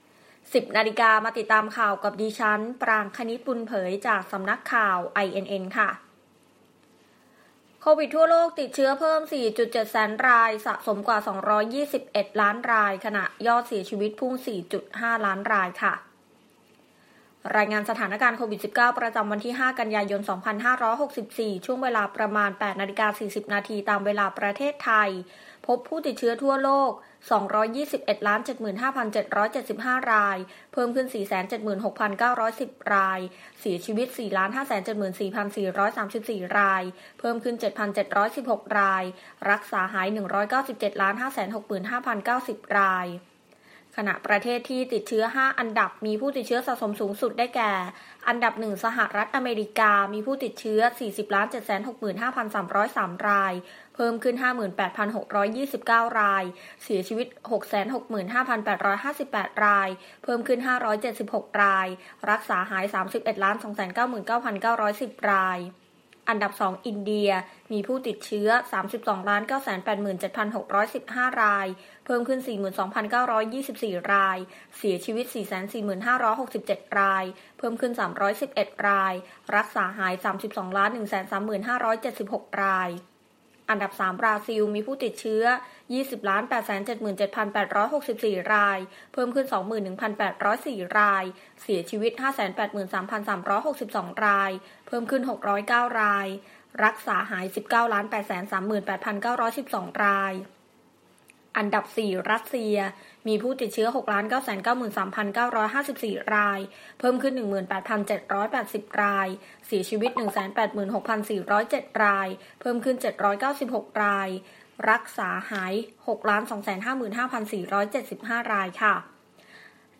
คลิปข่าวต้นชั่วโมง
ข่าวต้นชั่วโมง 10.00 น.